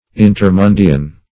intermundian - definition of intermundian - synonyms, pronunciation, spelling from Free Dictionary
Intermundian \In`ter*mun"di*an\, a.